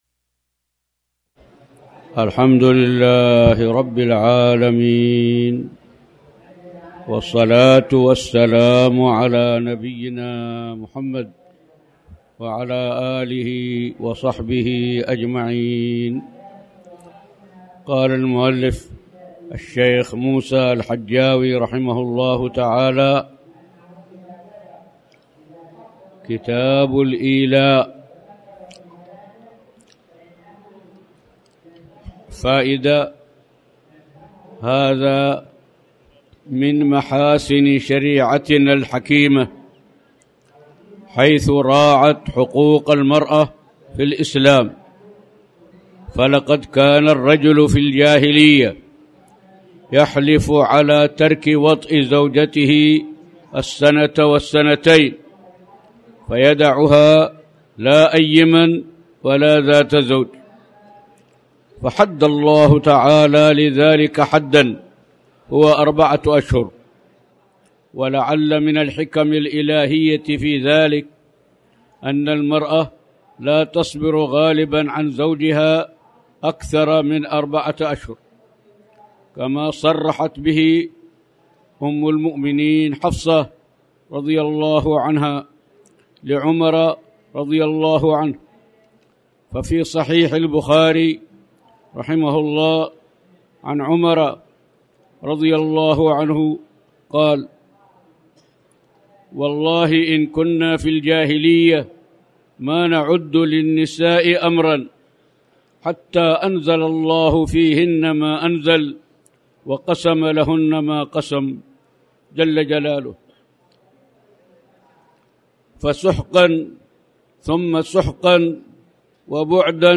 تاريخ النشر ٢٩ شعبان ١٤٣٩ هـ المكان: المسجد الحرام الشيخ